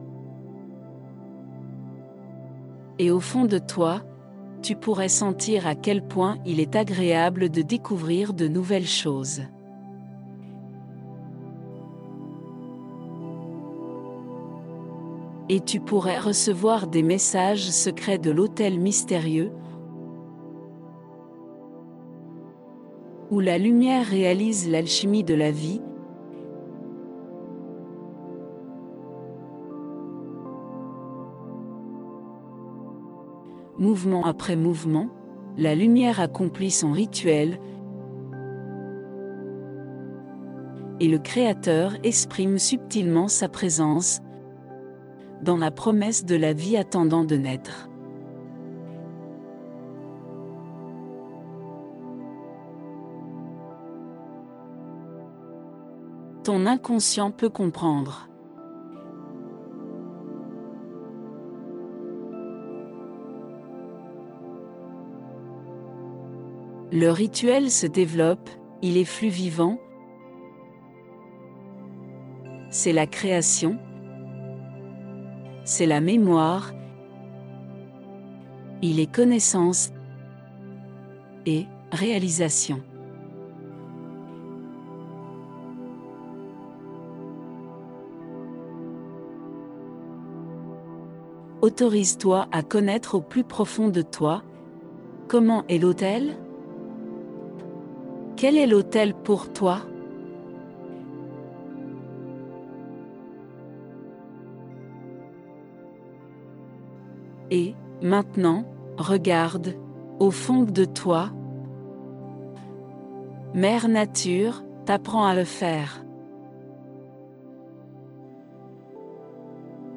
• J’ai mis ici deux extraits de cette méditation.